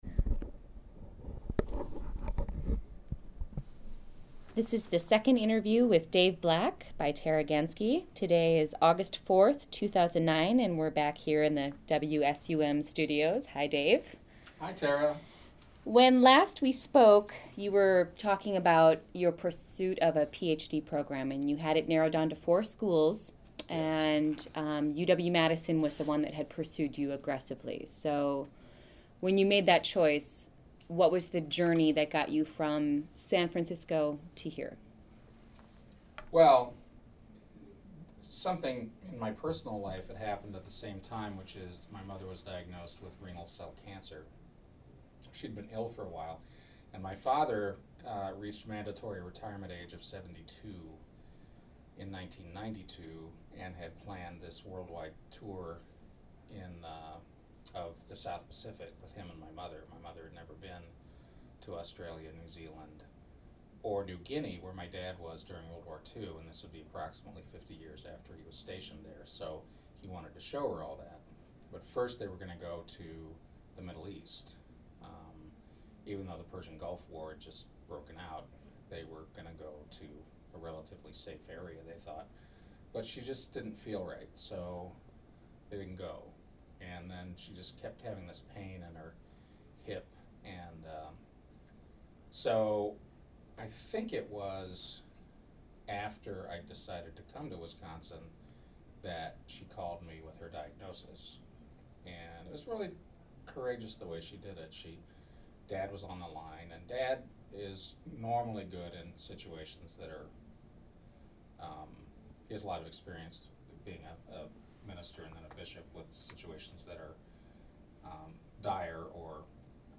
Oral Hisotory Interview